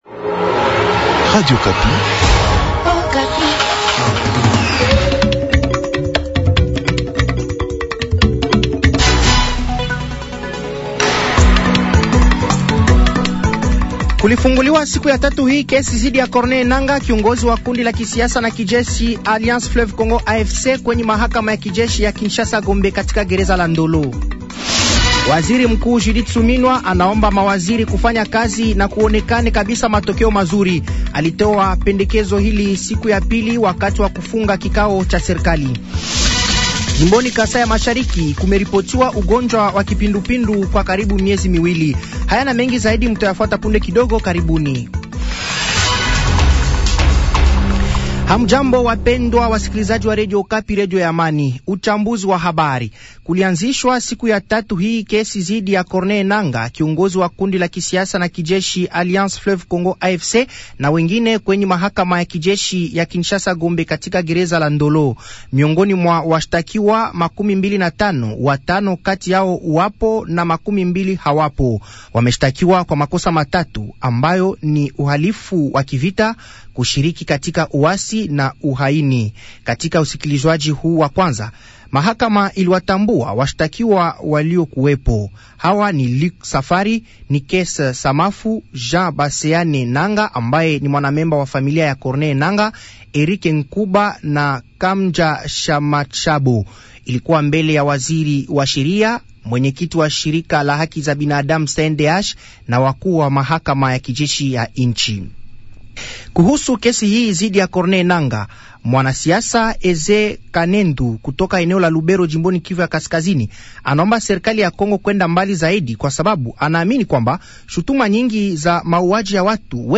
Habari za siku ya inne asubuhi tarehe 25/07/2024